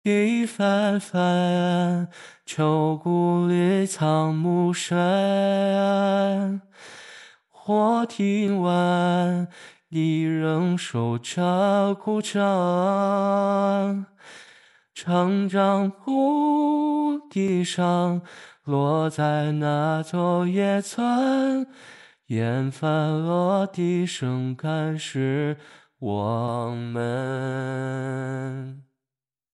一个温青男 青竹 RVC模型
模型声线属于温青，如果声线比较攻可以出青叔音，对唱歌表现还行，使用时尽量压低声线，如果效果不太理想，可以调整音调来解决。
数据集采集了30分钟的数据，其中为了达到比较好的一个效果，内置了包括，笑，尖叫等多种与数据集音色差不多的音色进行合成，所以对语气这一方面可能会稍微好一丢丢。